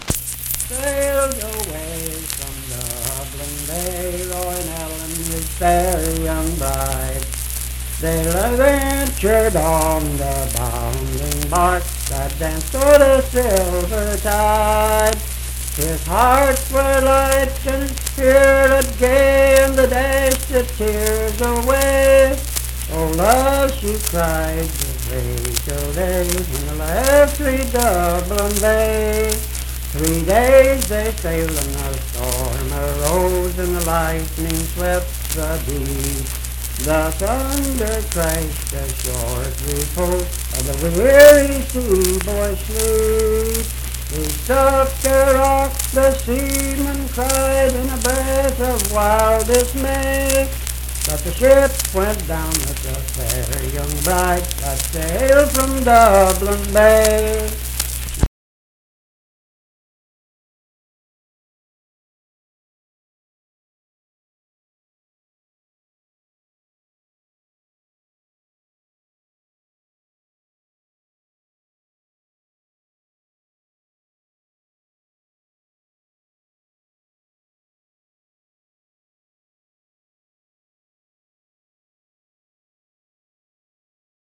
Unaccompanied vocal music
Verse-refrain 2(8).
Voice (sung)